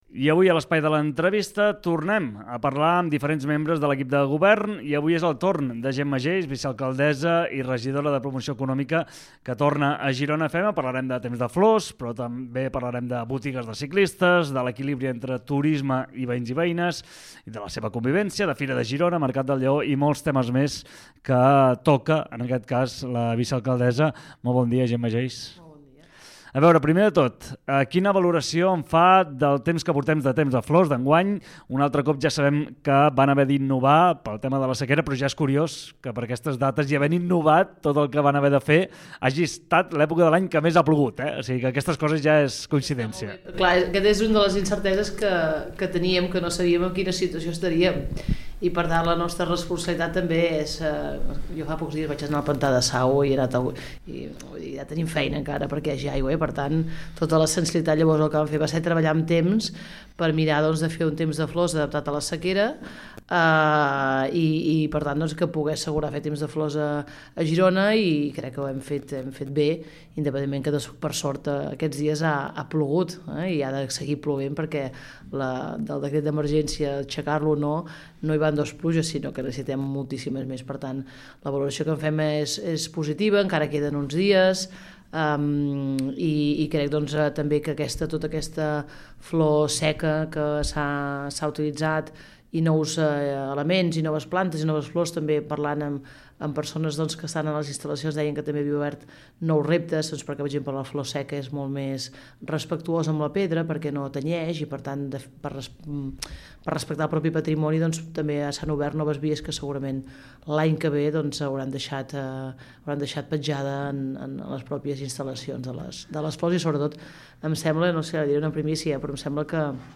Entrevista personal a la Regidora de Contractació i Patrimoni Mariàngels Planas a GironaFM